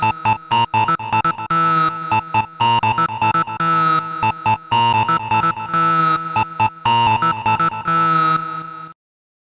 Guess it largely depends on what you mean by “gritty”, but here are some (poorly played) not-pleasant patches I made this afternoon.
No external FX, played straight from the Super6 into an audio interface (TASCAM Model16, which is pretty clean).